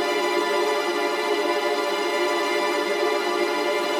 GS_TremString-Ddim.wav